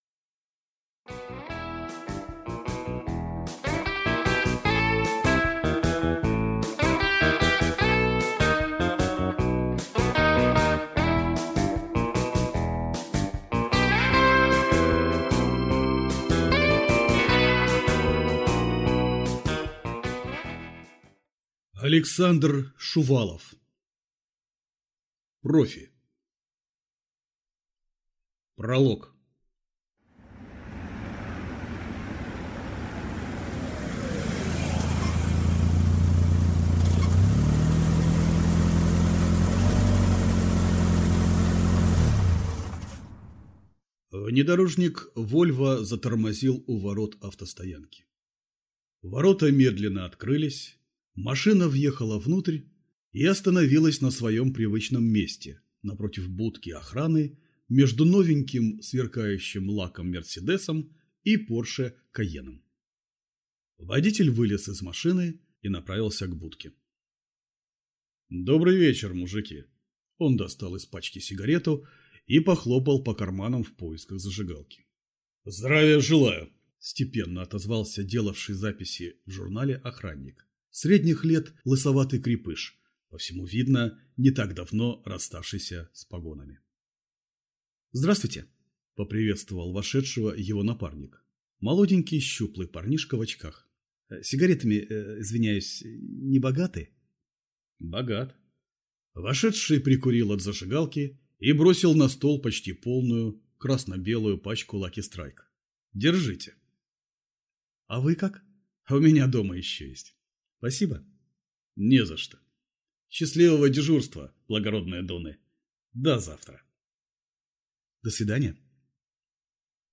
Aудиокнига Профи